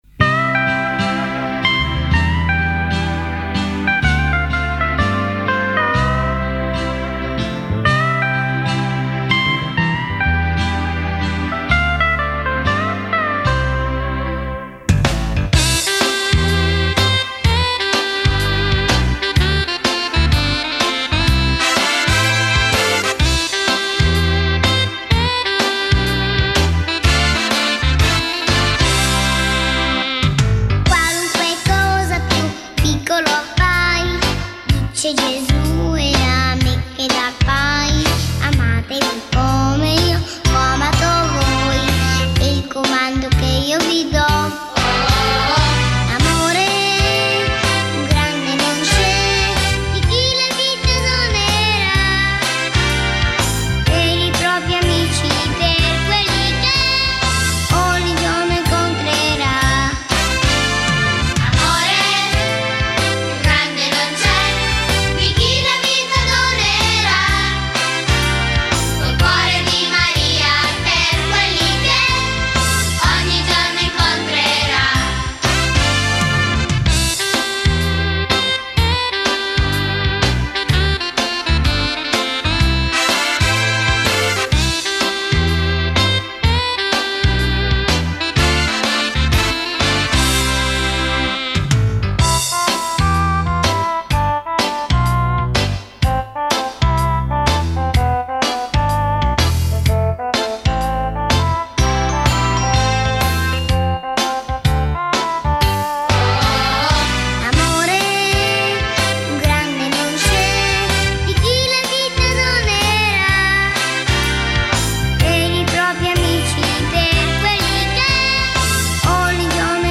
Canto per la Decina di Rosario e Parola di Dio: Amatevi come io ho amato voi